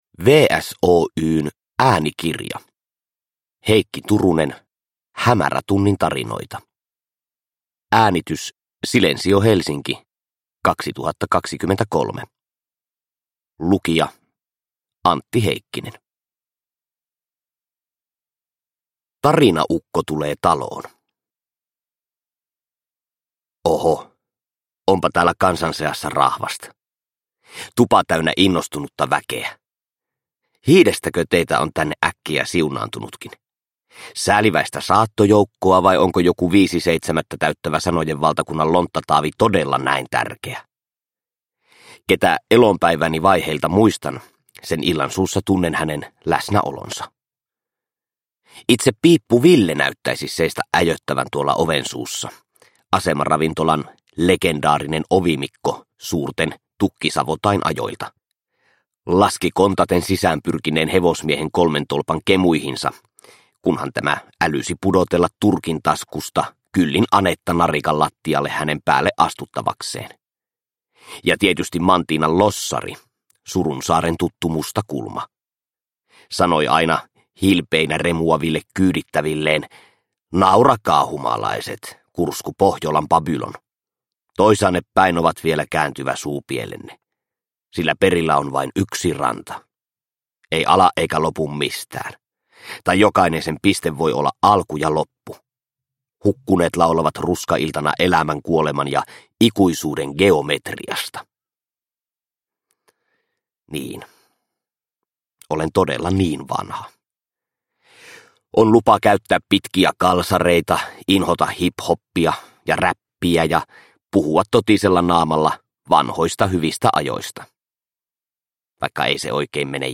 Hämärätunnin tarinoita – Ljudbok – Laddas ner